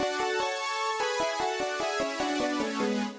太い音も出ますし超お勧めなんですが、現在は有償版しかない模様。
コンプレッサーとコーラスを掛けてます。